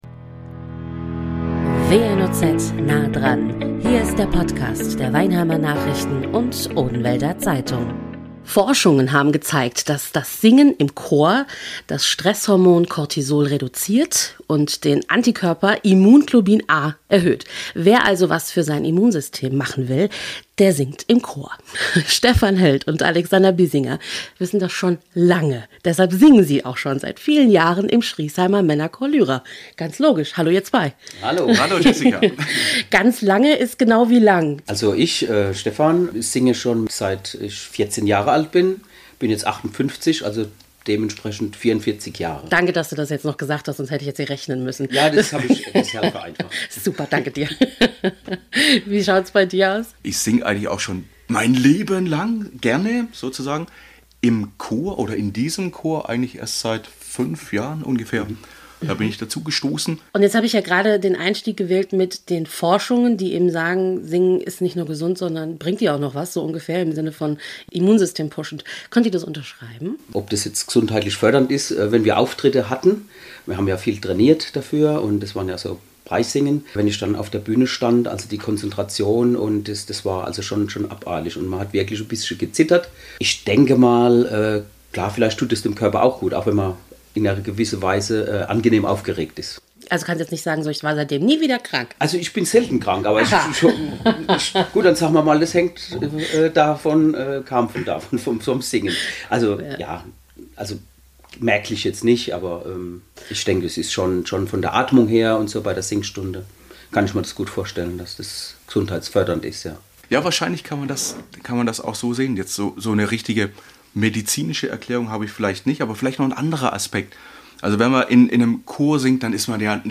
Studiogäste